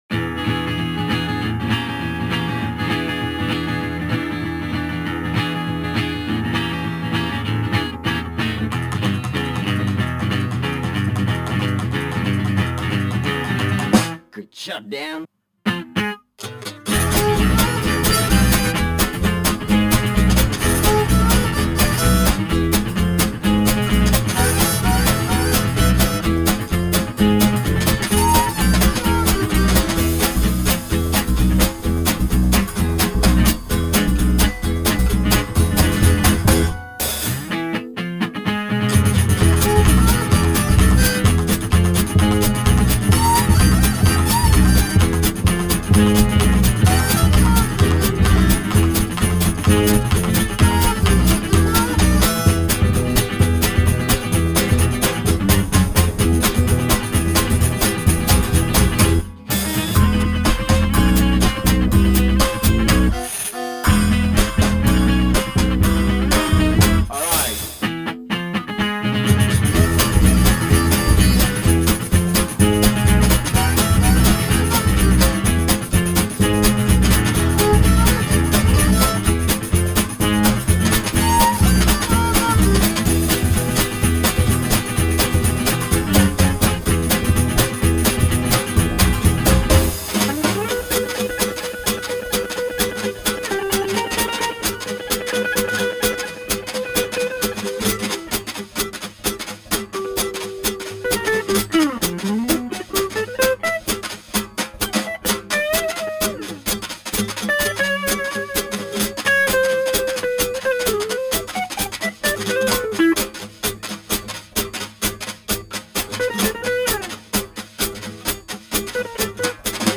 Francoski rock’n’roll s primesmi etna na poti na Balkan.